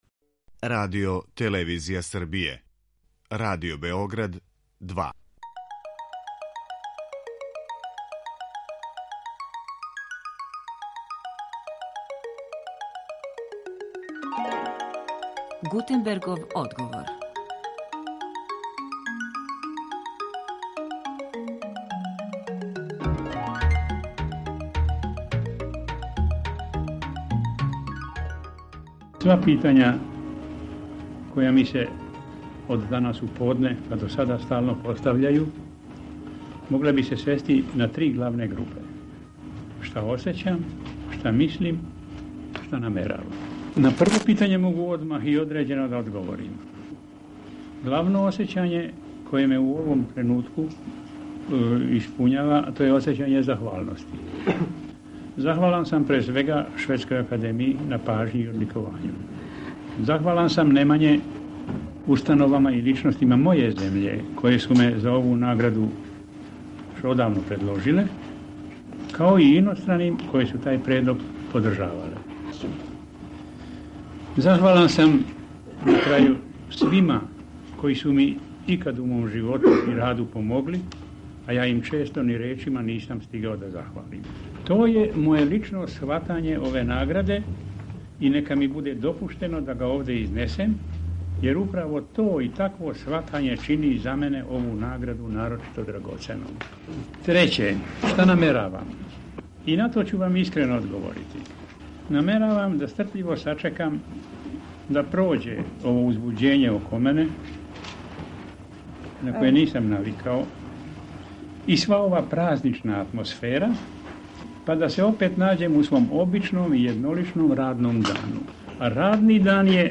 На свечаној академији одломке из најчувенијег Андрићевог романа „На Дрини ћуприја" говорио је глумац Тихомир Станић. Емисију ћемо отворити гласом нашег нобеловца и чути шта је он рекао на вест о добијању Нобелове награде за књижевност.